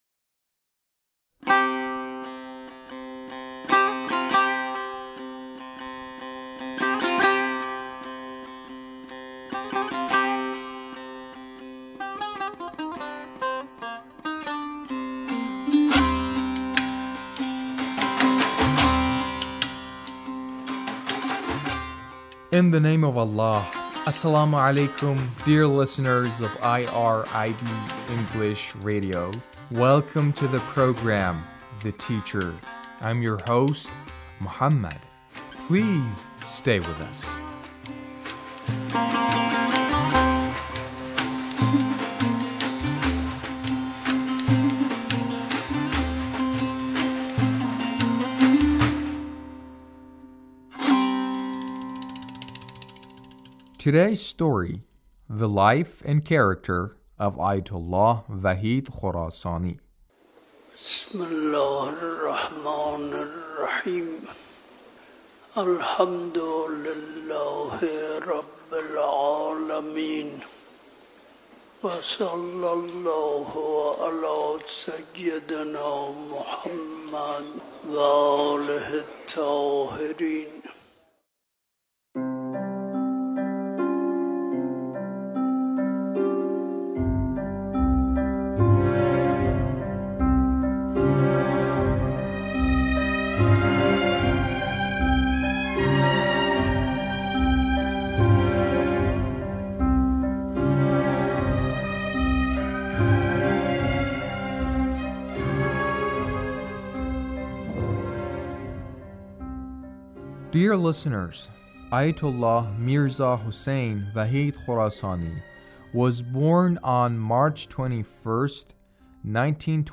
A radio documentary on the life of Ayatullah Wahid Khorasani